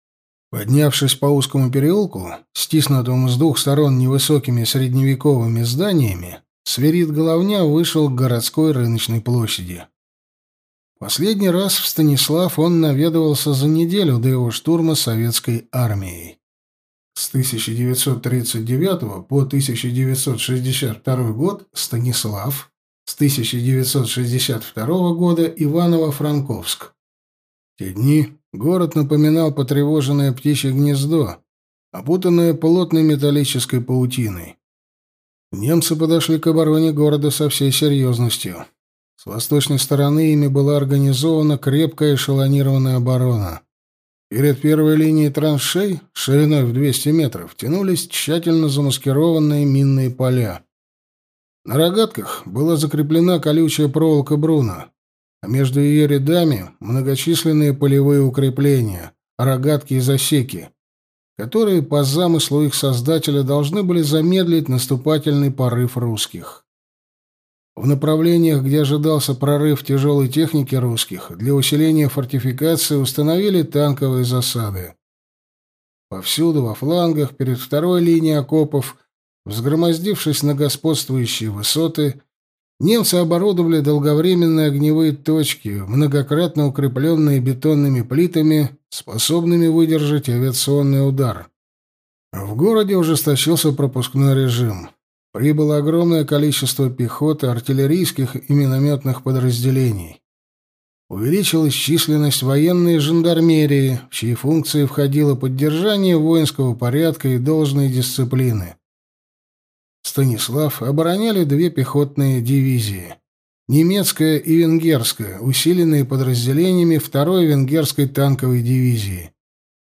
Аудиокнига Лесная банда | Библиотека аудиокниг